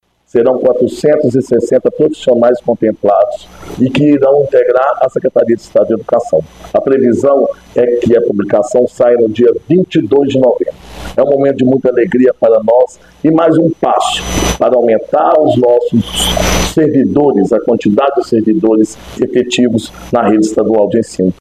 O secretário de Estado de Educação, Igor de Alvarenga, fala sobre as nomeações.